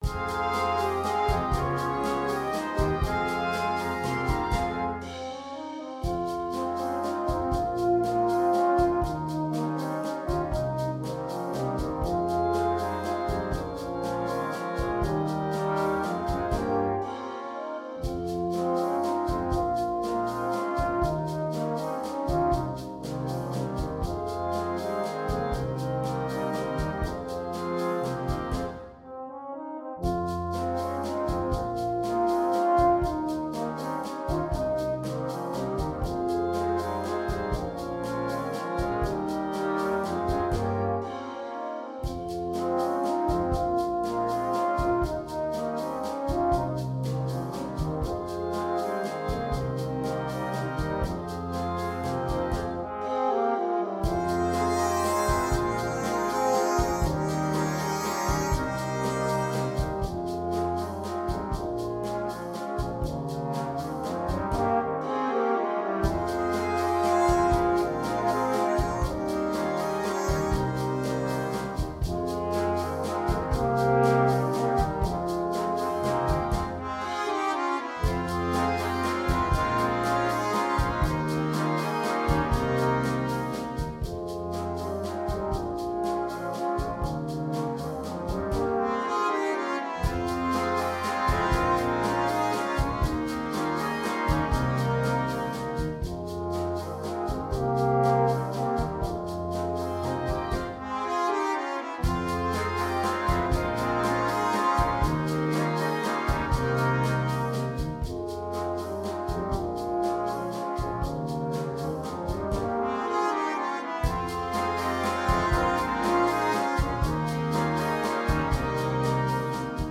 Noten für Blaskapelle
mit Soloinstrument
Tenorhorn / Bariton (Duett)
Unterhaltung